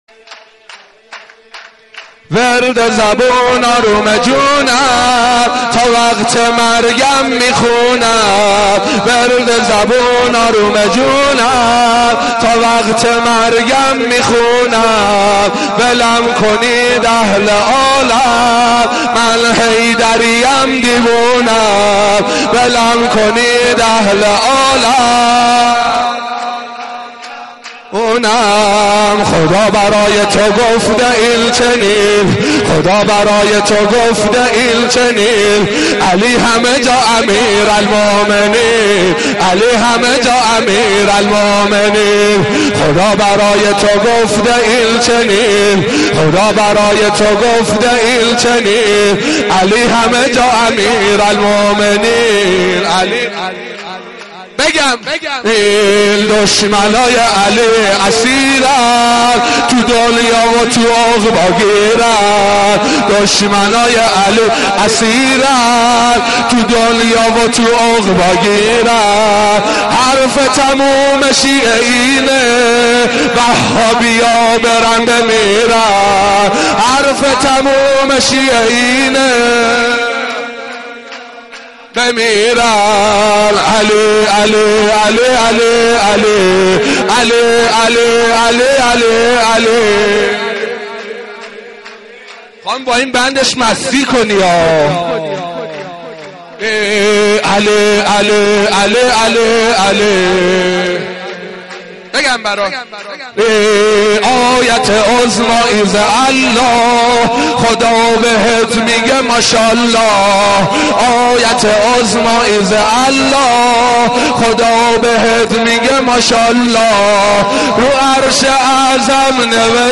• مولودی امیرالمومنین, ولادت امیرالمومنین, دانلود مولودی امیرالمومنین, سرود امیرالمومنین, رجز امیرالمومنین